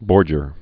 (bôrjər)